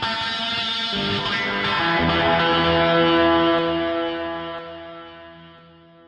独奏吉他切割" wah 4 55
描述：我的Epiphone Les Paul吉他通过Marshall放大器和cry baby wah踏板制作的简短吉他样本。
Tag: 电子 吉他 音乐 处理 华哇WHA